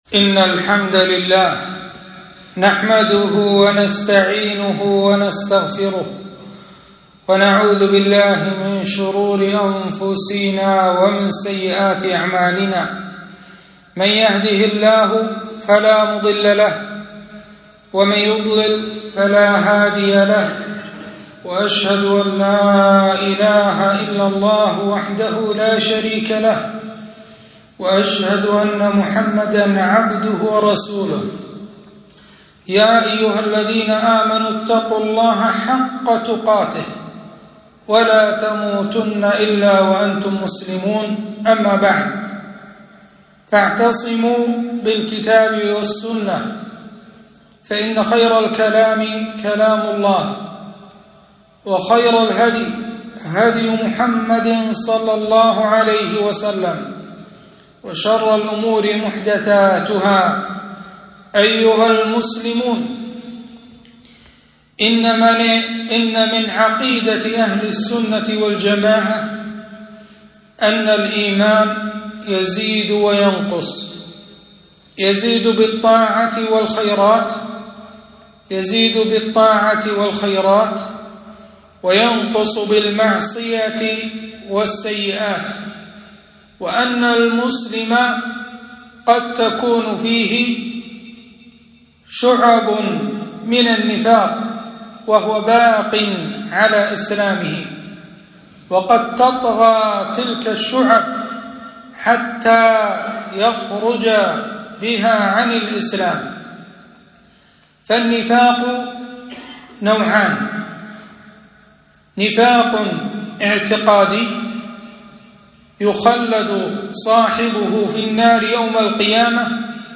خطبة - صفة النفاق ونعت المنافقين